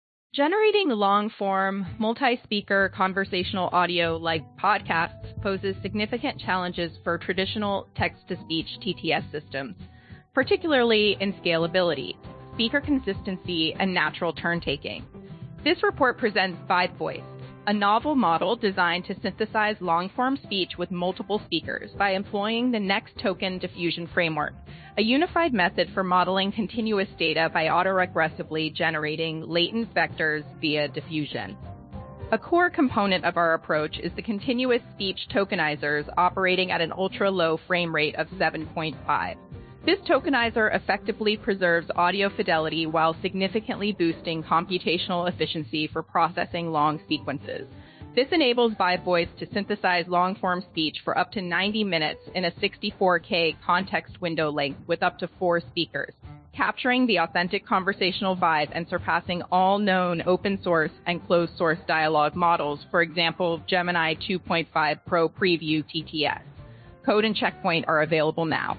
Microsoft's VibeVoice text-to-speech model that can generate long-form speech from text with sample voices.
"speaker_1": "en-Alice_woman",